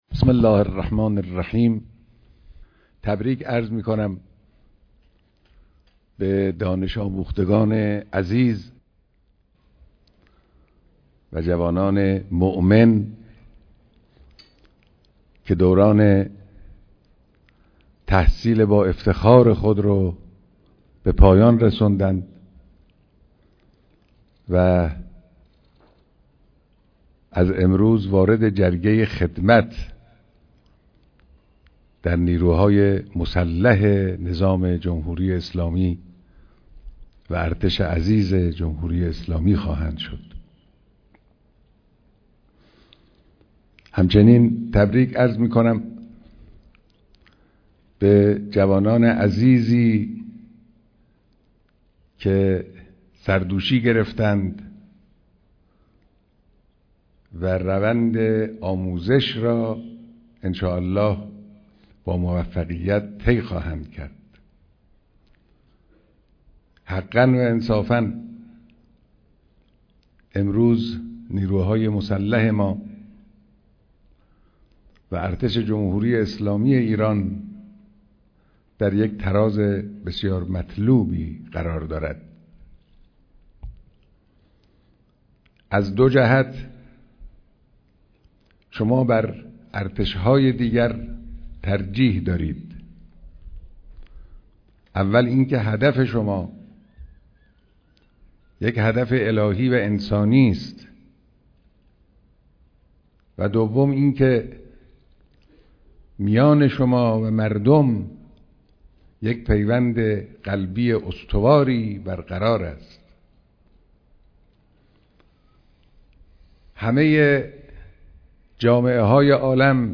بیانات در مراسم فارغ‌التحصيلى دانش‌آموختگان ارتش